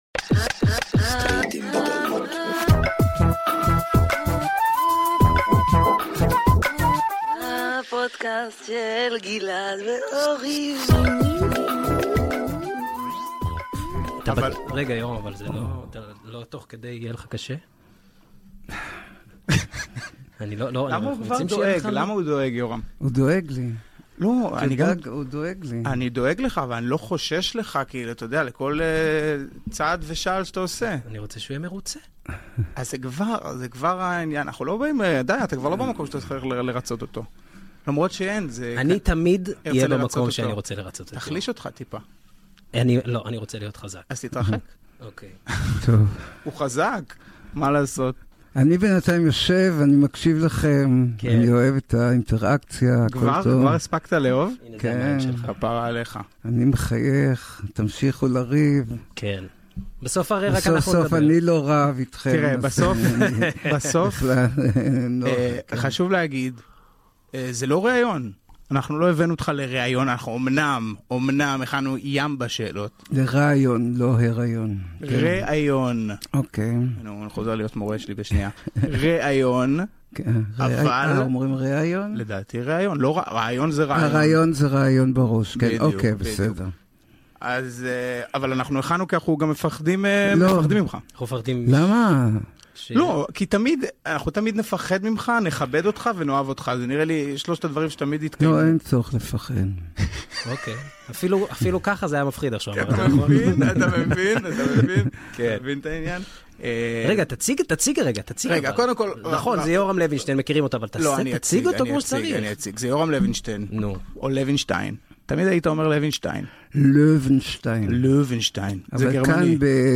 תודות: אולפן הפודאסט של בית אריאלה Download episode Share Share Copy URL Subscribe on Podcast Addict